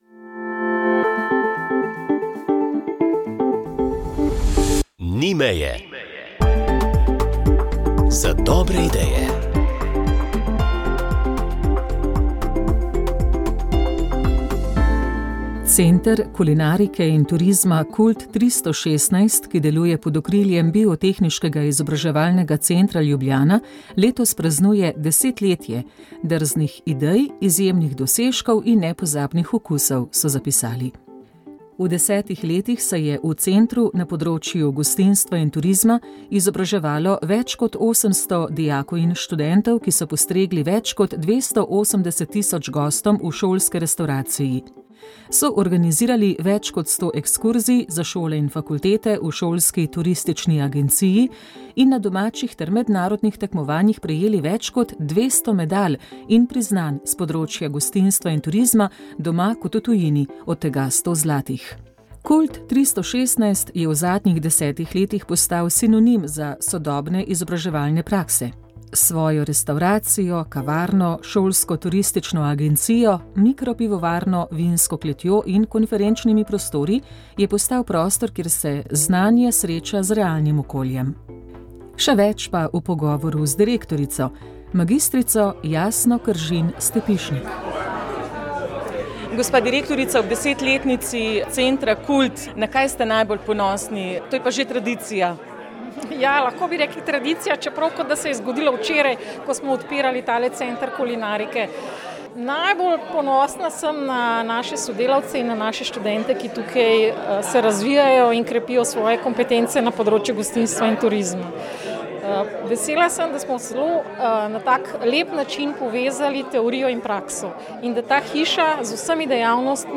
Zgodbe za otroke duhovnost mladi otroci odnosi šmarnice pravljice zgodbe